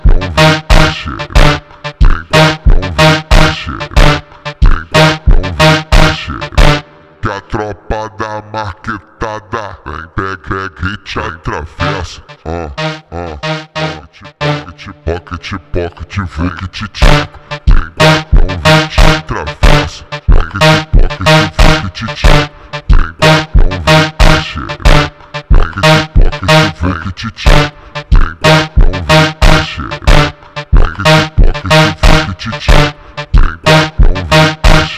Brazilian